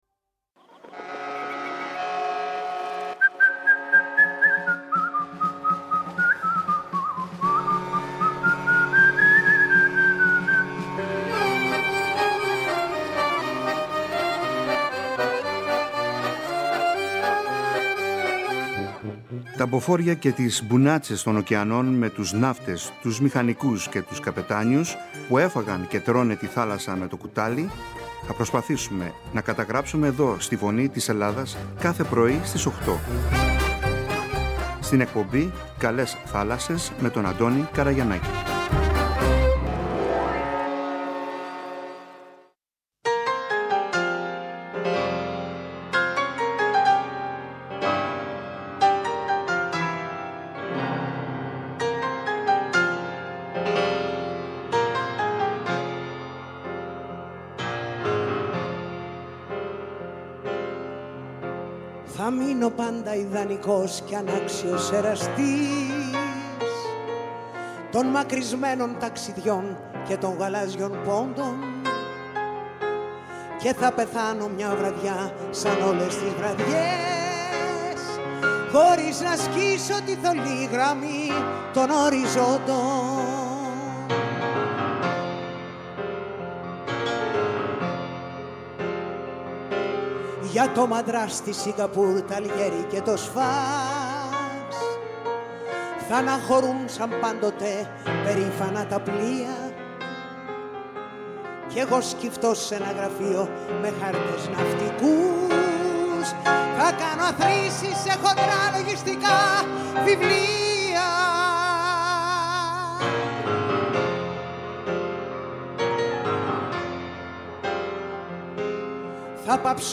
Ο καλεσμένος μιλάει για την αγάπη του στη θάλασσα και για τη ναυτιλία τότε και σήμερα, προτρέποντας τους νέους να επιλέξουν αυτό το επάγγελμα.